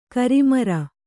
♪ karimara